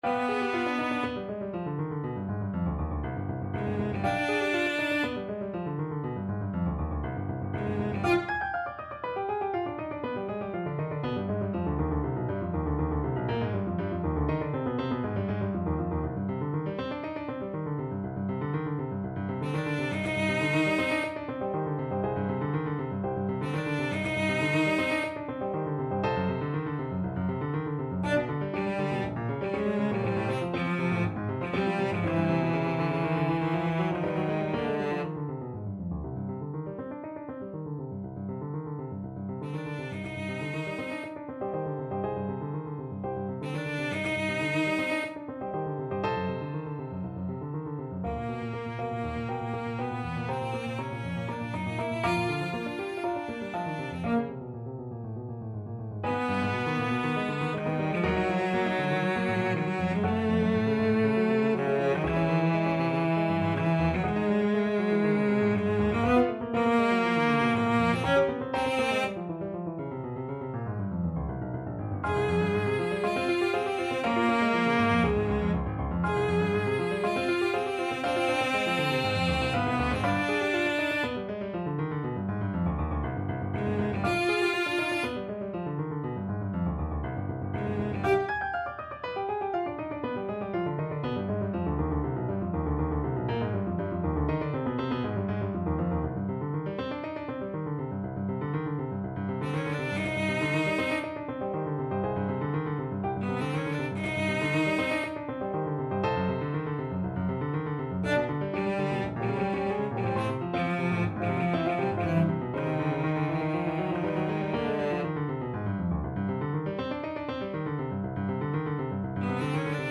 Cello
C minor (Sounding Pitch) (View more C minor Music for Cello )
Allegro con fuoco (View more music marked Allegro)
4/4 (View more 4/4 Music)
Classical (View more Classical Cello Music)